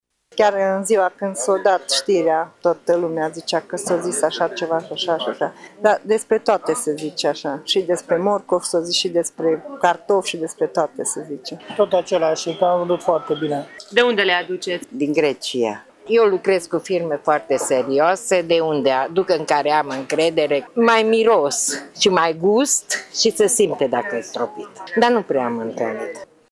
Piețarii în schimb spun că vânzările de citrice nu au fost afectate, dar clienții se interesează dacă fructele au fost stropite și cu ce anume: